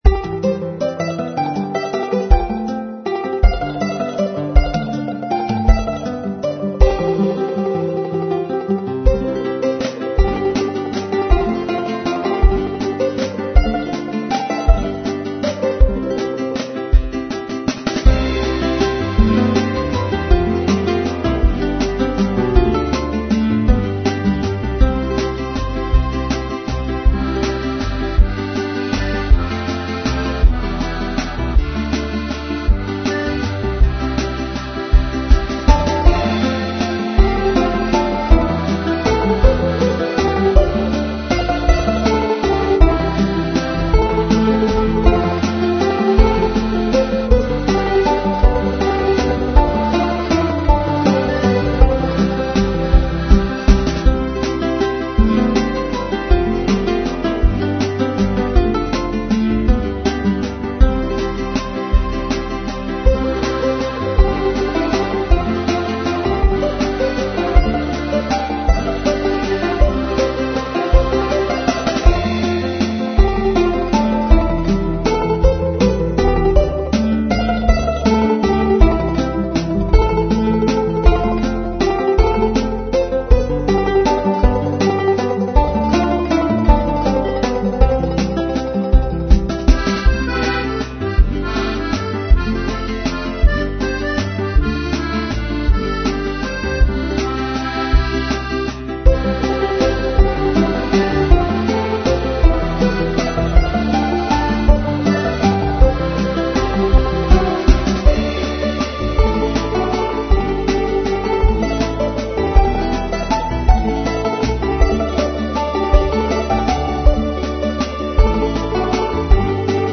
Simple Italian-Like Folk music